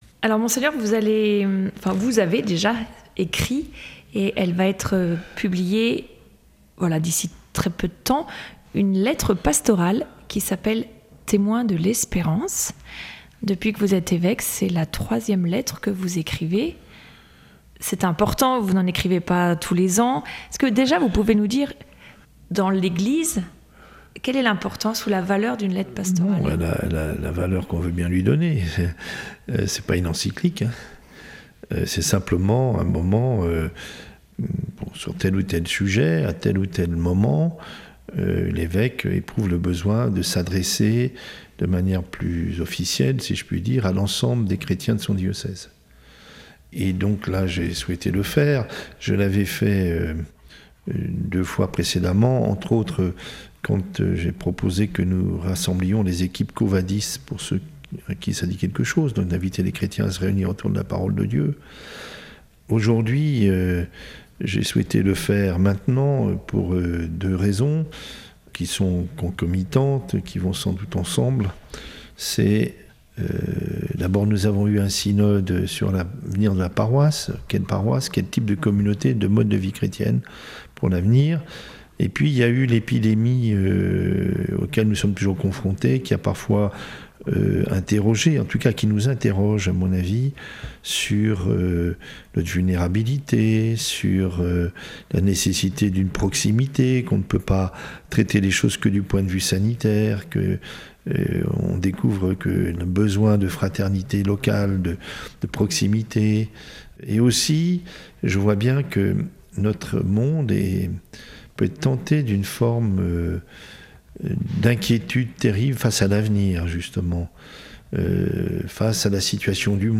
Mgr Le Saux nous parle de sa lettre pastorale dans « Eglise en Sarthe » d’RCF Sarthe